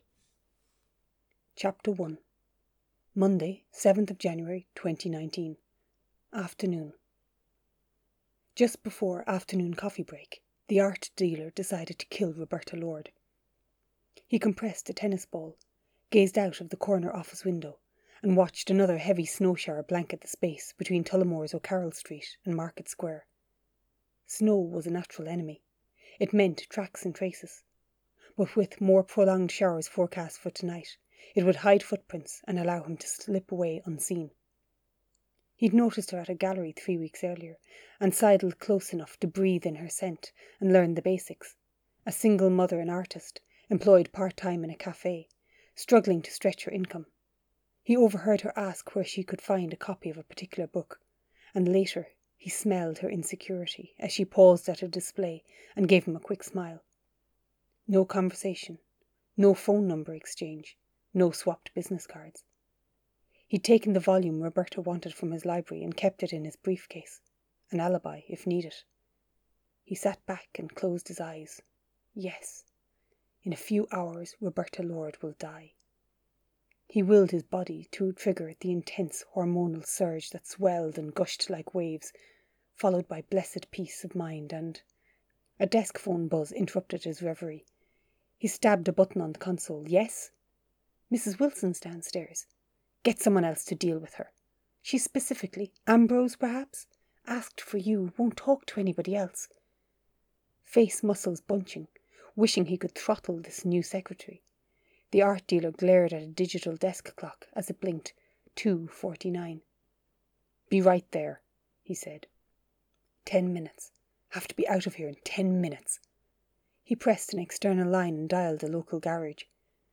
[ Audio Clip ] Opening Snippet